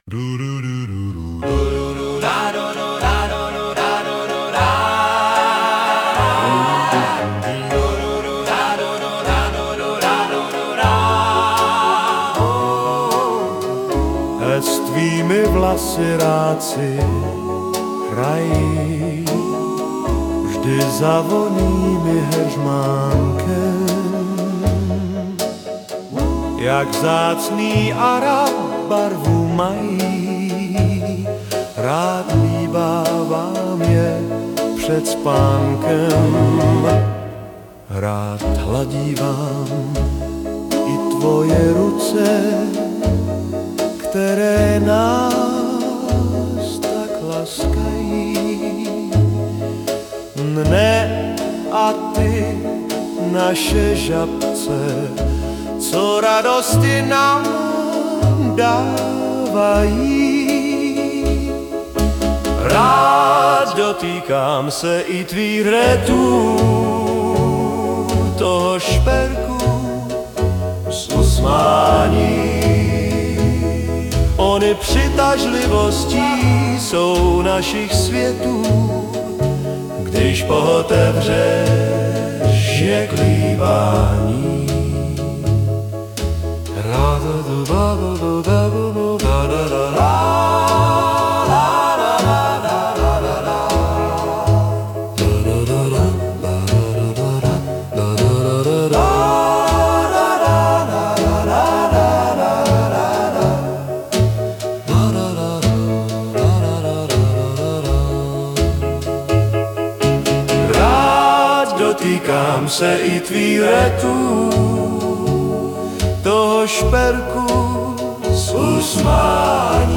hudba, zpěv: AI